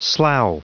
Prononciation du mot slough en anglais (fichier audio)
Prononciation du mot : slough